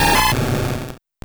Cri de Crocrodil dans Pokémon Or et Argent.